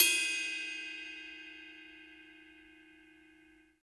D2 RIDE-12.wav